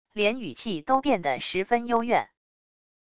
连语气都变的十分幽怨wav音频